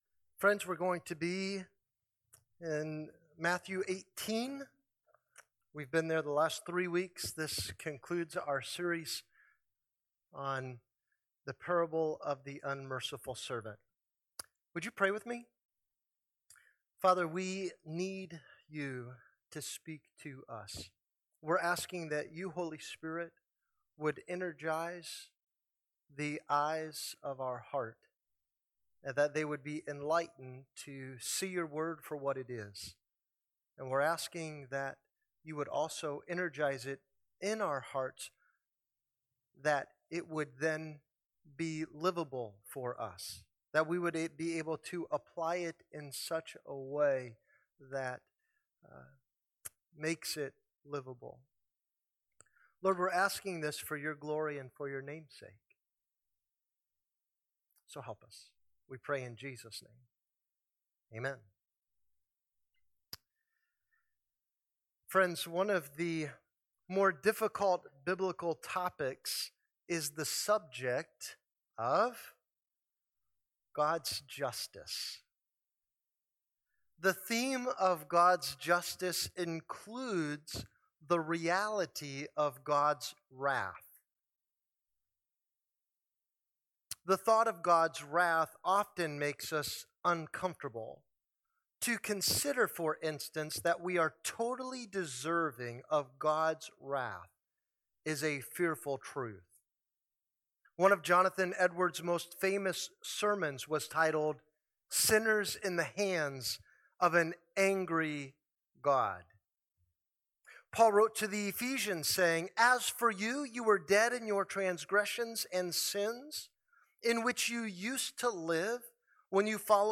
Sermons | California Road Missionary Church
Guest Speaker